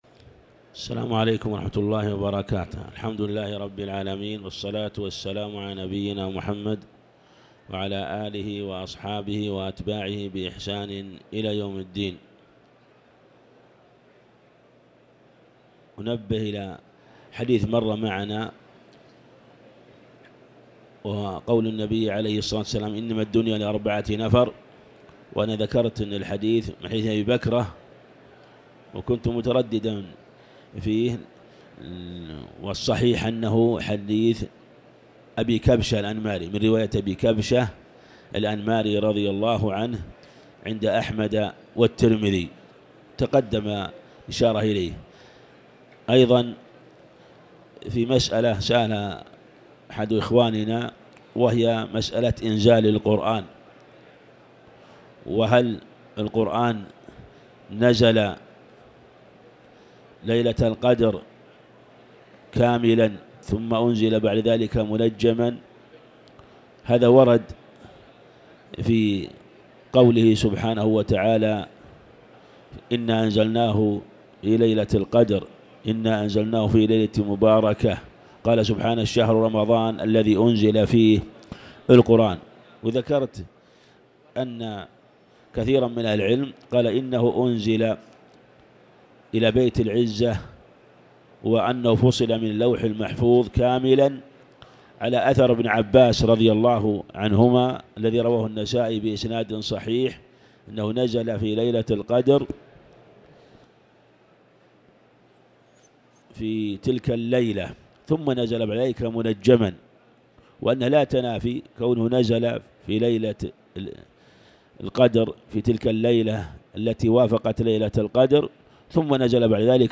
تاريخ النشر ١٥ رمضان ١٤٤٠ هـ المكان: المسجد الحرام الشيخ